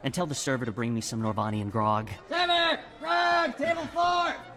―The bouncer gives Timmy Katarn's drink order — (audio)
However, as his name is only delivered in shouted dialogue, the exact pronunciation and spelling of his name is unknown and conjectural, respectively.[2]
NarShaddBouncer_Timmy_Grog_Table_Four.ogg